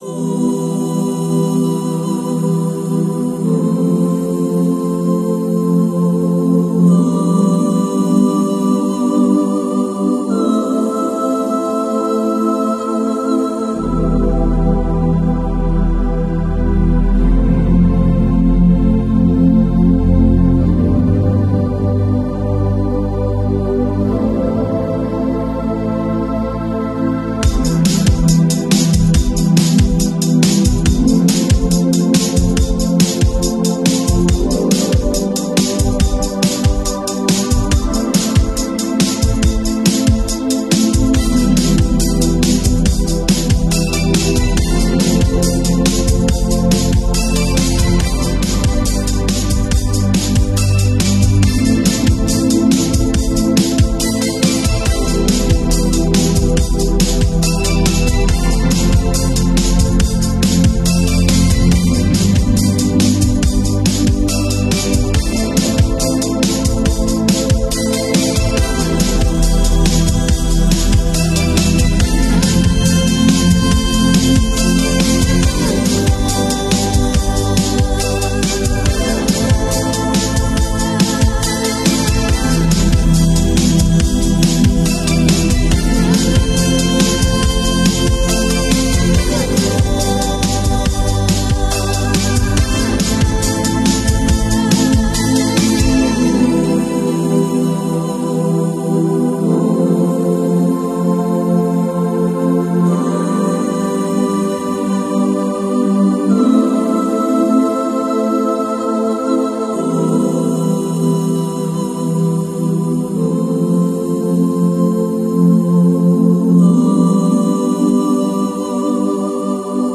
This calming frequency supports your nervous system and restores inner balance.